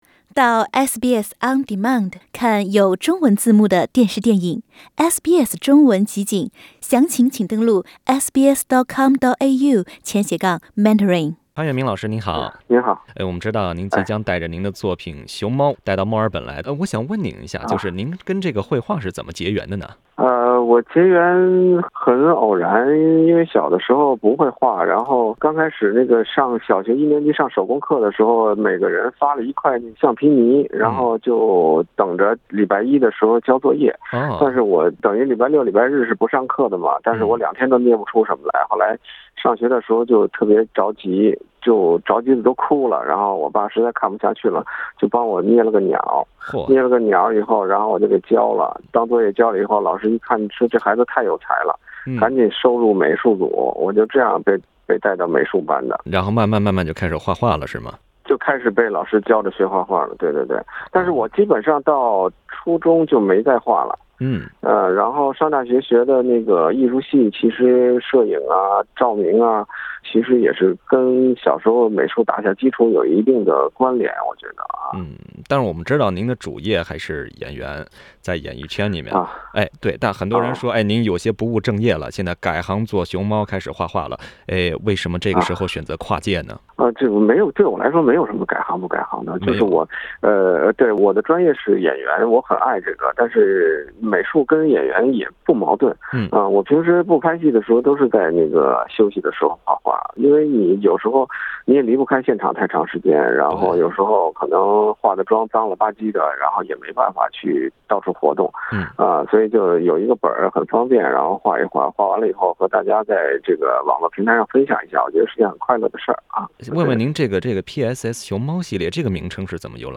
他在接受本台记者采访时说“绘画是一种心灵的按摩”，甚至觉得“绘画生涯”要长于“演艺生涯”。